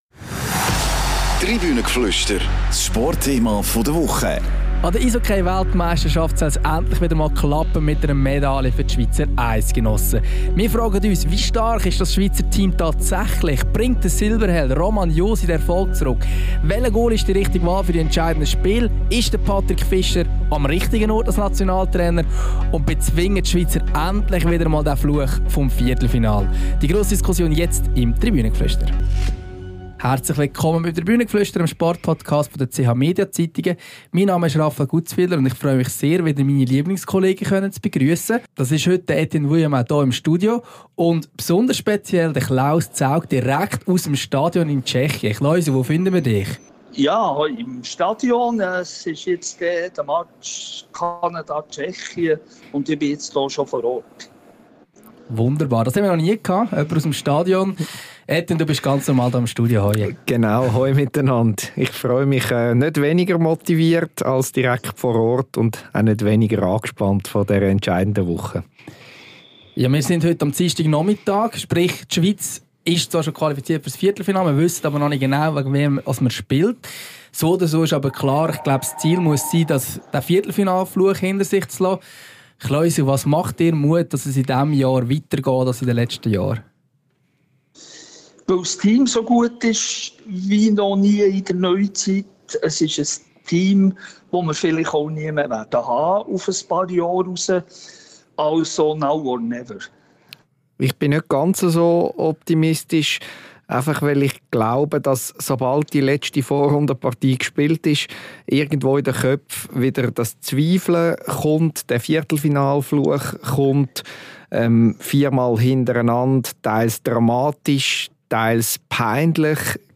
Hören Sie jetzt die Debatte im Tribünengeflüster.